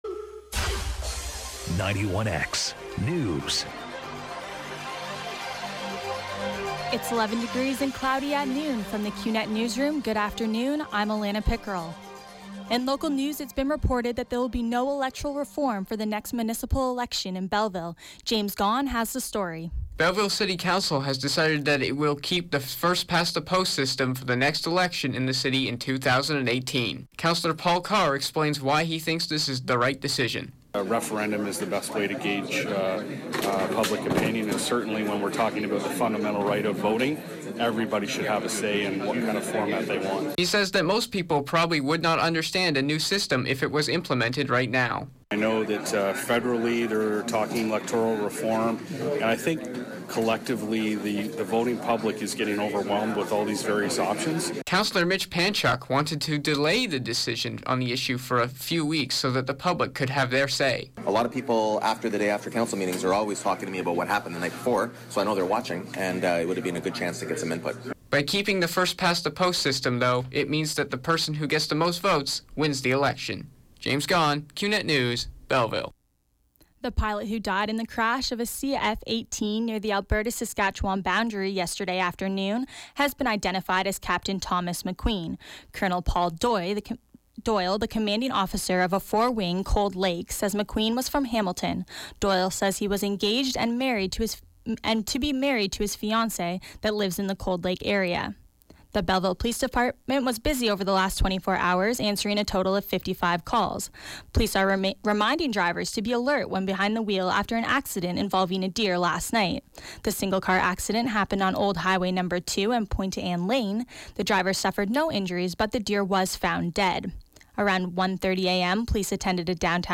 91X FM Newscast – Tuesday, Nov. 29, 2016, 12 p.m.